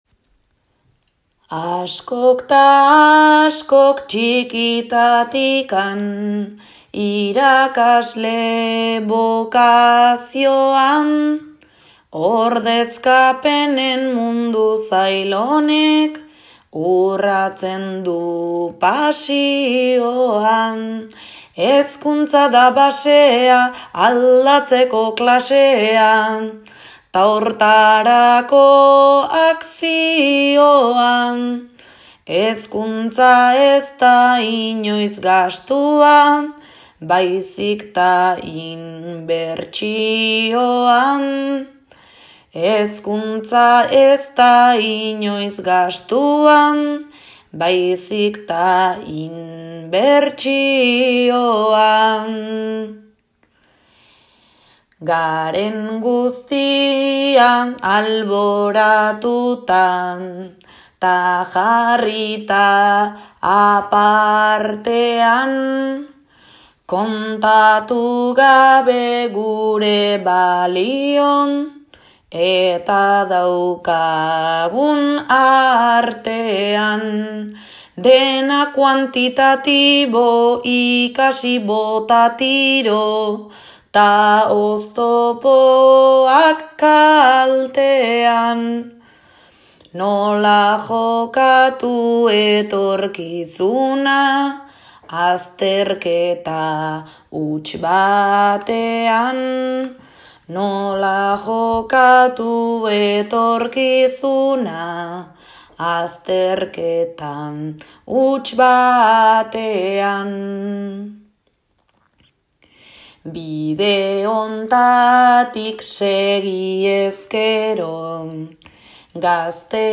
hezkuntzari jarritako bertsoekin.